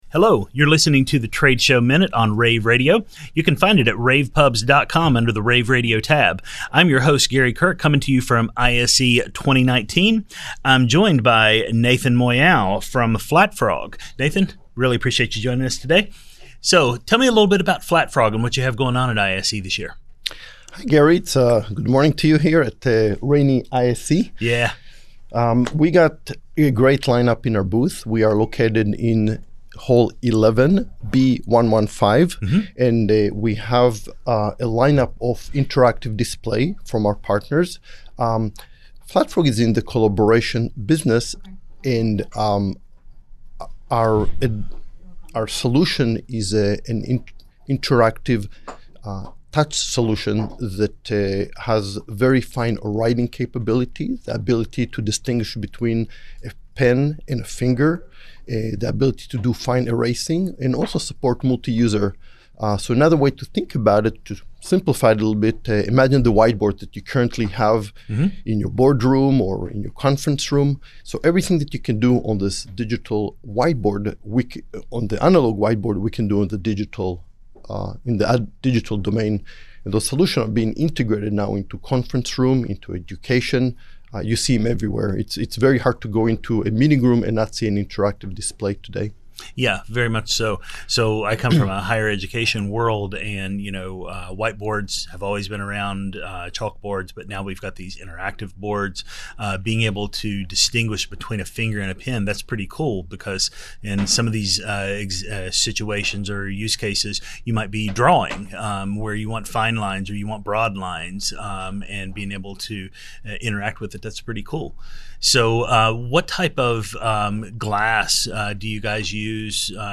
February 6, 2019 - ISE, ISE Radio, Radio, rAVe [PUBS], The Trade Show Minute,